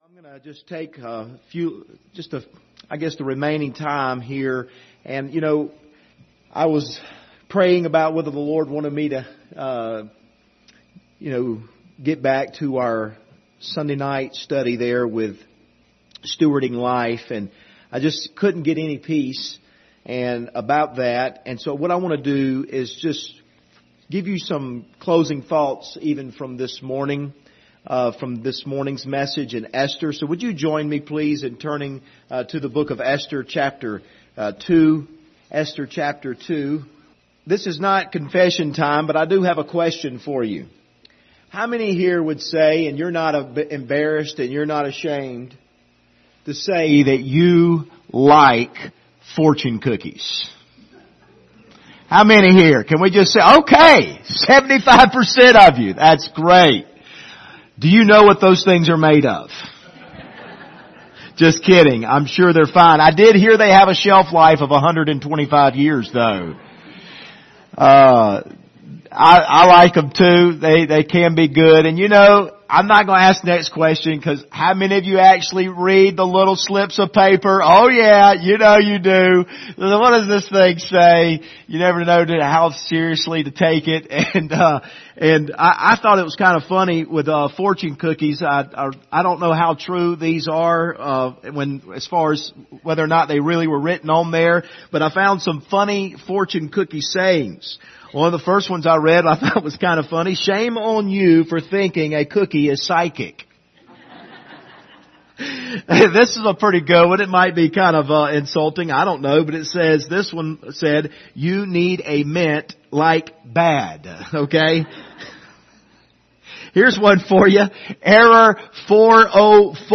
Passage: Esther 2 Service Type: Sunday Evening